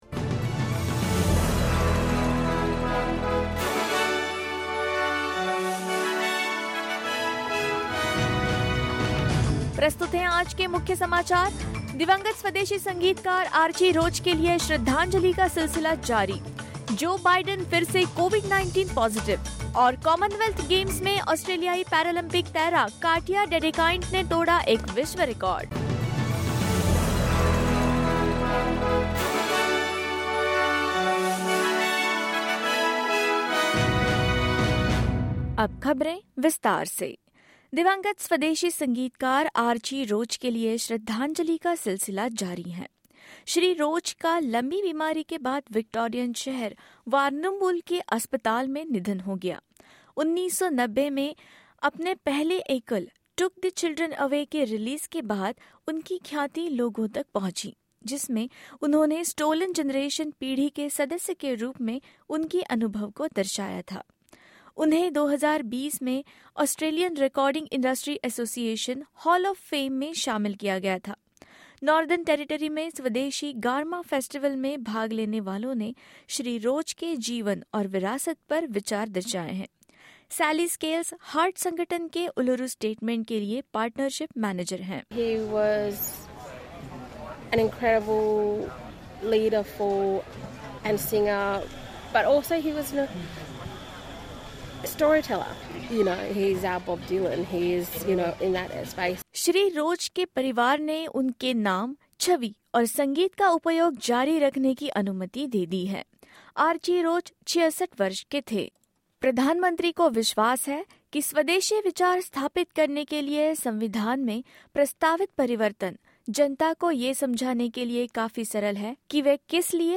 In this latest SBS Hindi bulletin: Celebrated Aboriginal musician Archie Roach passes away after a long battle with illness; US President Joe Biden tests positive again for COVID-19; Australian paralympic swimmer Katja Dedekind breaks world record at Commonwealth Games and more.
hindi_news_3107_0.mp3